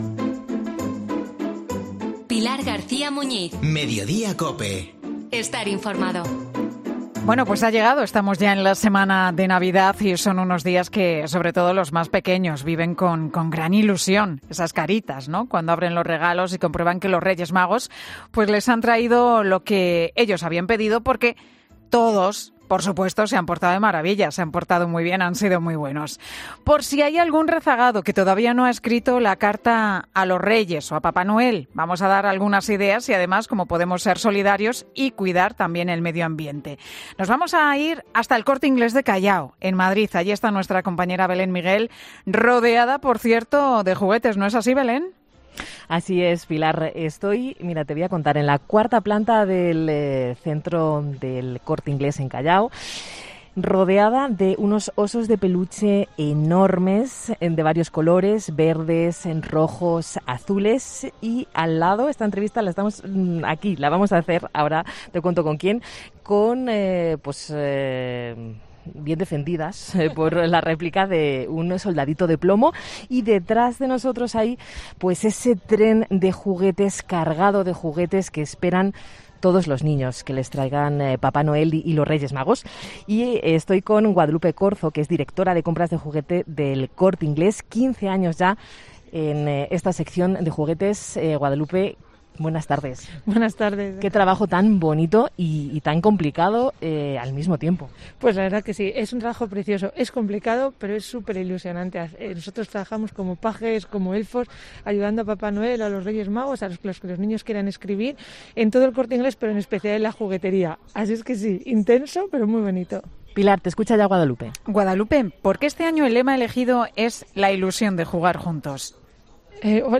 Descubre sus respuestas en directo desde El Corte Inglés.